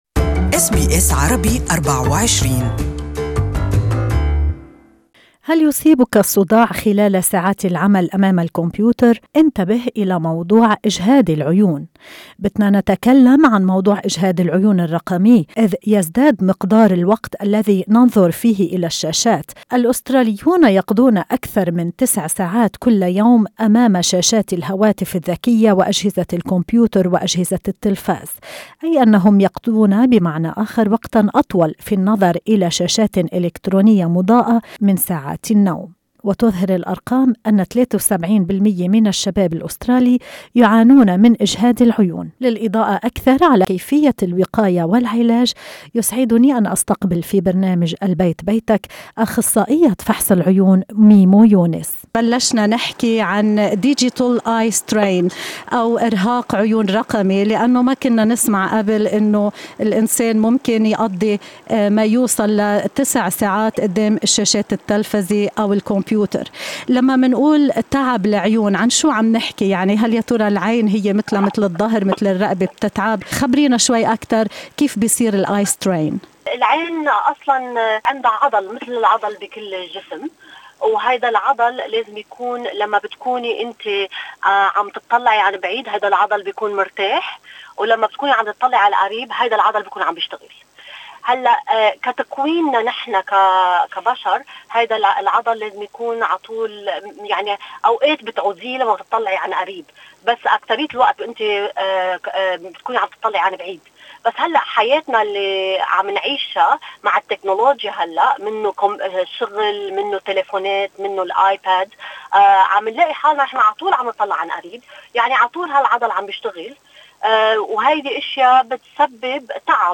في هذه المقابلة